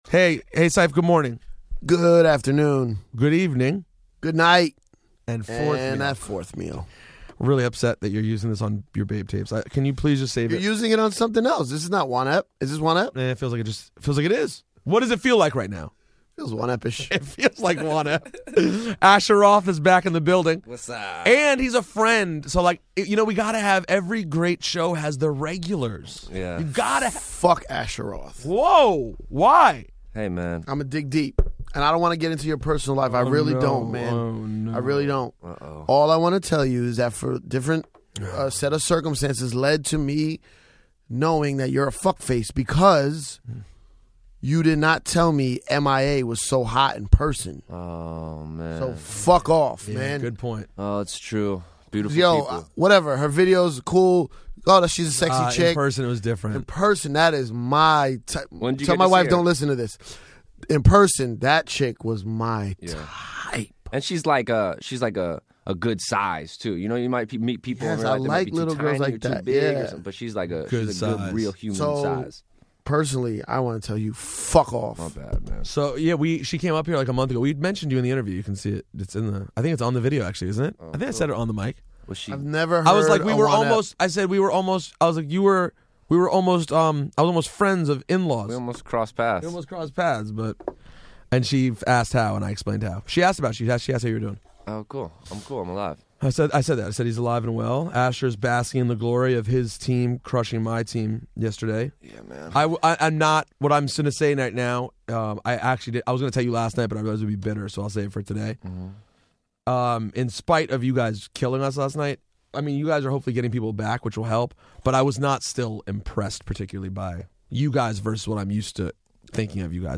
So calm your nerves and enjoy this great sitdown with our friend Ash Roth!